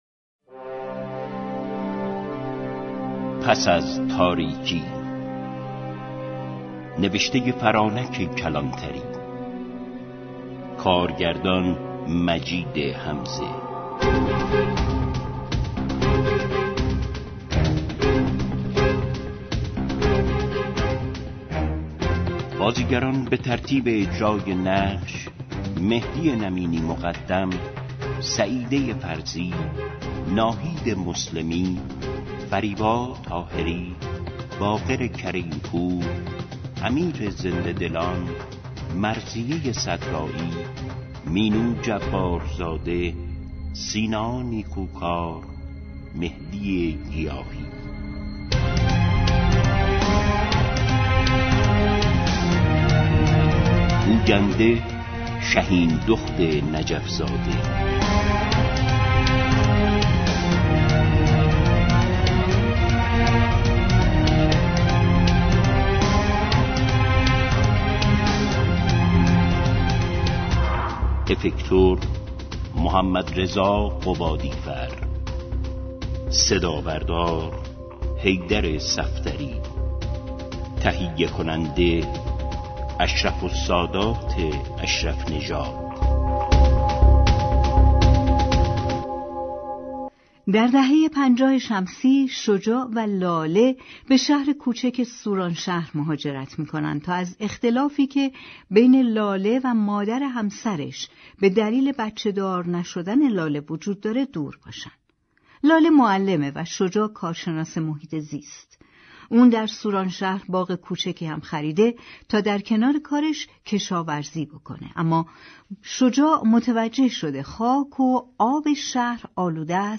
در این نمایش رادیویی